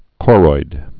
(kôroid) or cho·ri·oid (kôrē-oid)